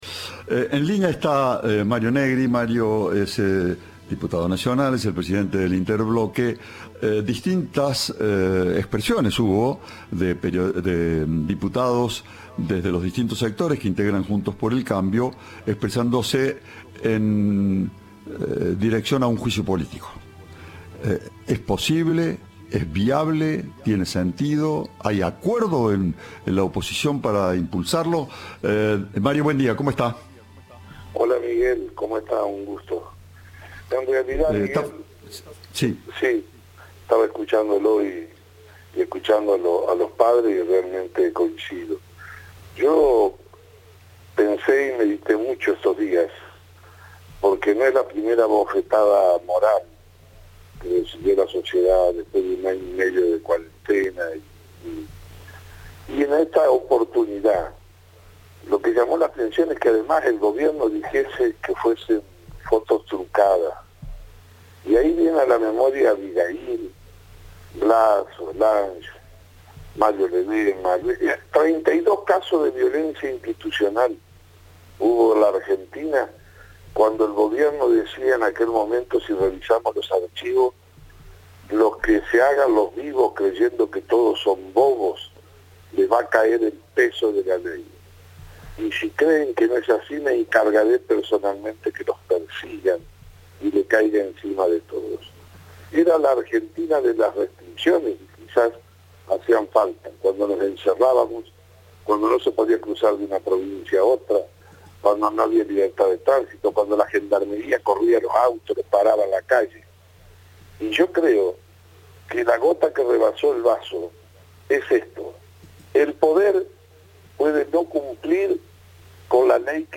Lo confirmó a Cadena 3 el diputado nacional Mario Negri, en referencia a la imagen donde se puede ver al Presidente y a su pareja alrededor de una mesa con sus asesores, sin mantener la distancia y sin ningún tipo de protocolo.